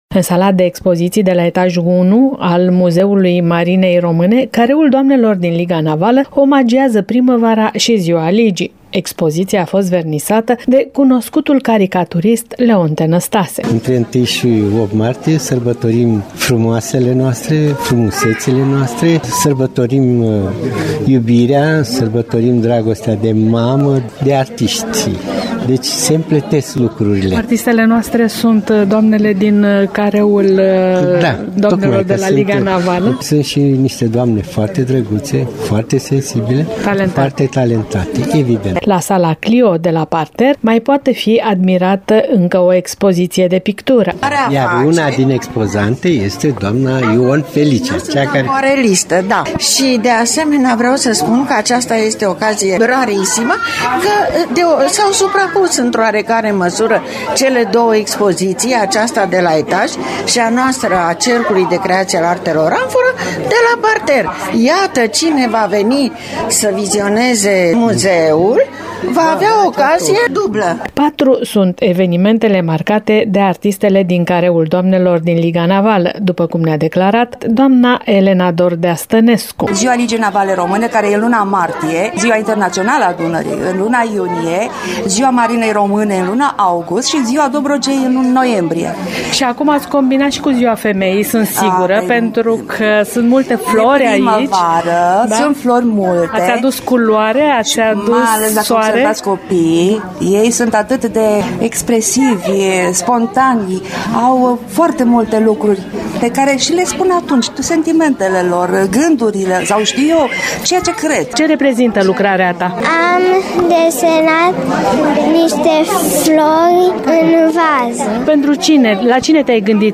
Vernisajul expoziției a avut loc astăzi, 5 martie, în prezența reprezentanților Ligii Navale Române filiala Constanța și ai Muzeului Național al Marinei Române.